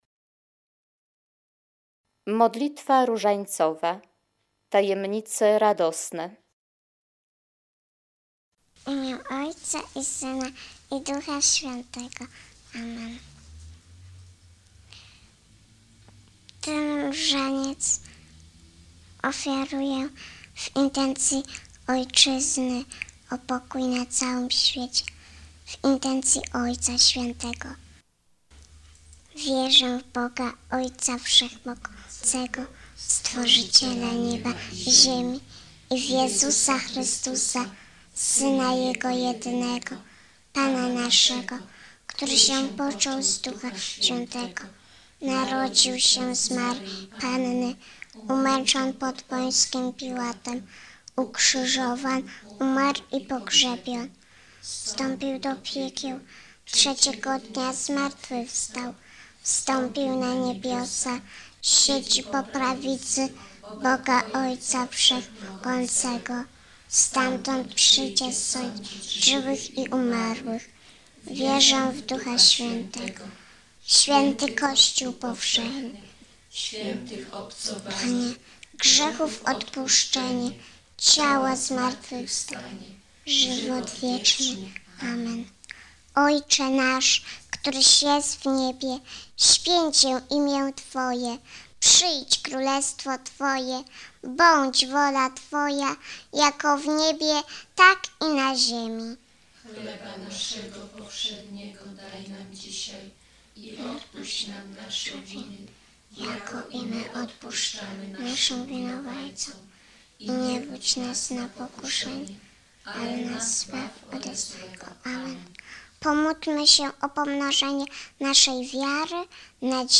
Modlitwa różańcowa